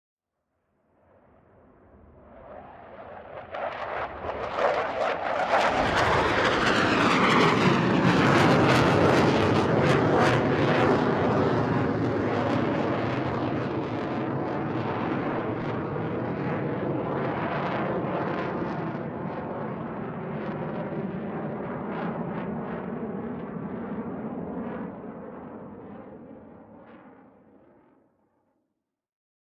AFX_F18_FLYBY_3_DFMG.WAV
F-18 Flyby 3